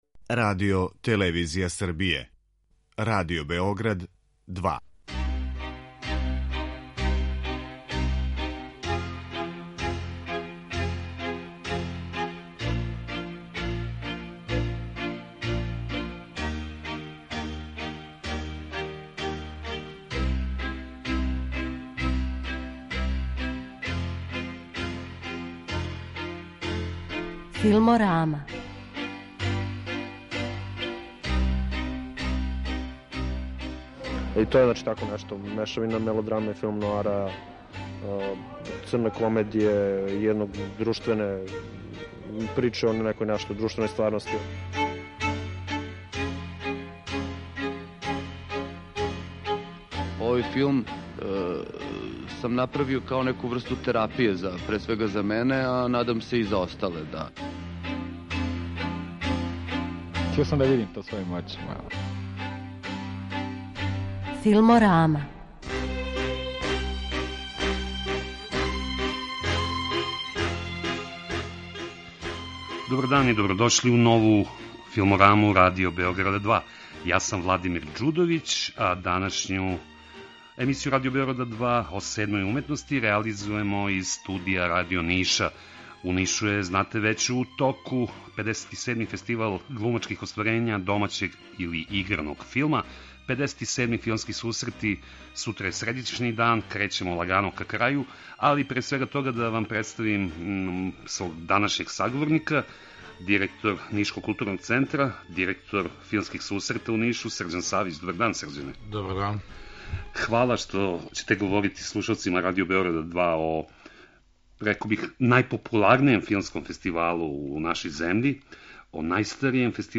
У току је Фестивал глумачких остварења домаћег играног филма који се у Нишу одржава већ 57 година. Из студија Радио Ниша са гостима и учесницима фестивала разговара